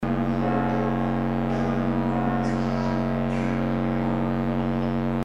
Remove strange noise - VideoHelp Forum
I have an old recording from a church with a very annoying noise (I think there were microphones hanging from the roof) that I haven't been able to remove so far. I checked the file in Sound Forges Spectrum Analysis (screen shot attached) and there is a repetitive peak that comes every 100 Hz.
It covers too much of the audio spectrum to eliminate.
noise.mp3